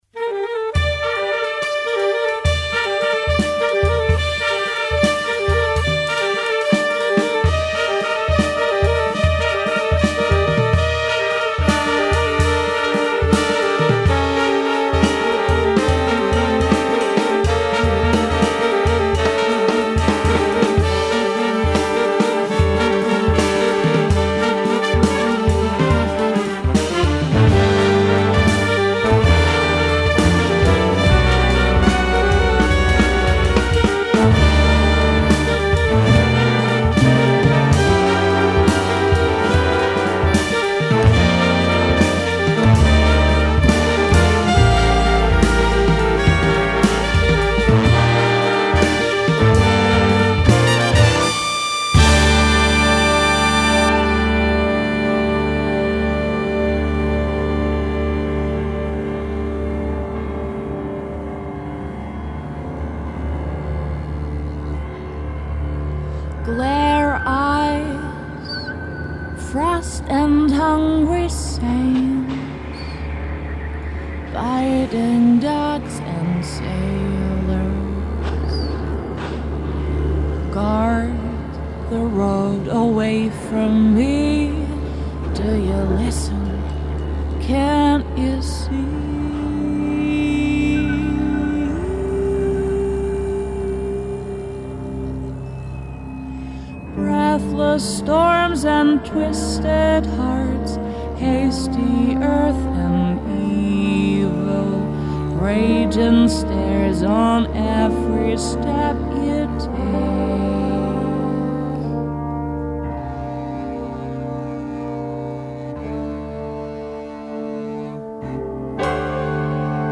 Contemporary big band jazz.